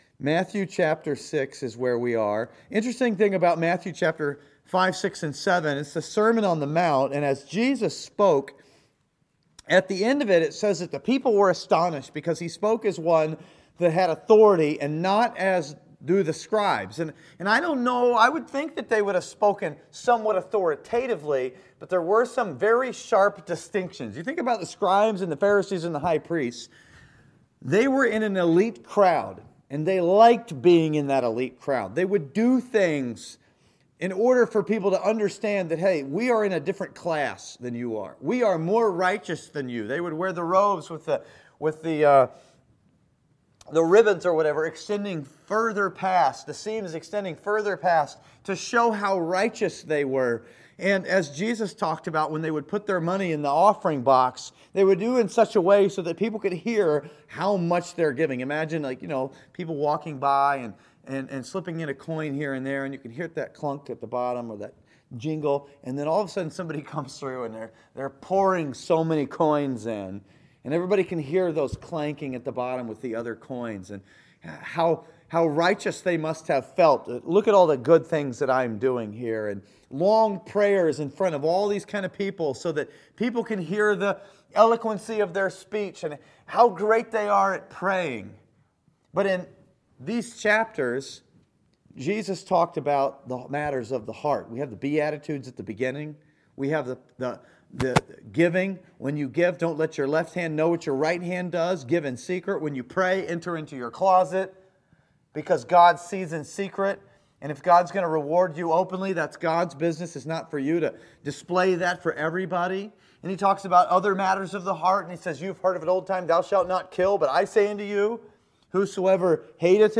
This sermon from Matthew chapter 6 encourages Christians to take no thought and not worry about life but rather to trust God.